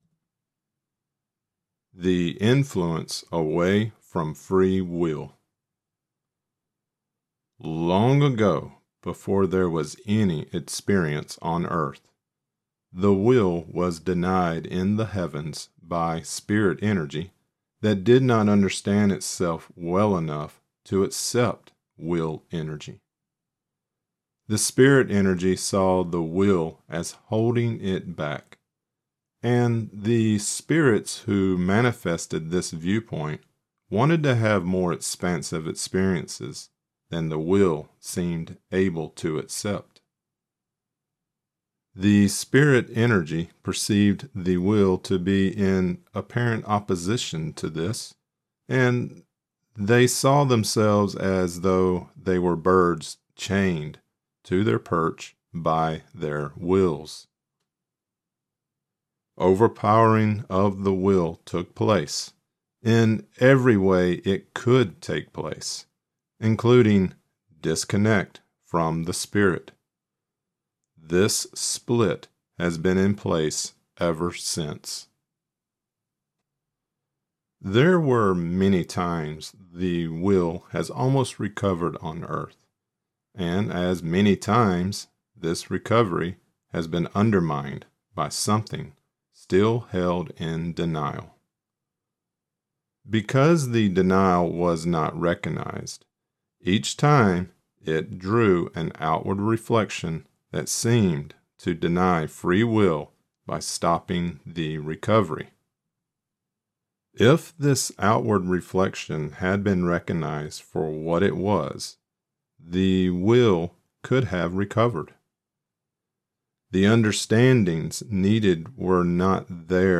This is part 15 of the Right Use of Will and this lecture includes: The Influence Away From Free Will There are two sections in part 15: * Prior to Earth * Wisdom Lecture Created Transcript Blockchain The Influence Away From Free Will 12/12/2025 The Influence Away From Free Will (audio only) 12/12/2025 Watch Right Use of Will part 15 lecture: Right Use of Will lectures are also located on the Cosmic Repository video site .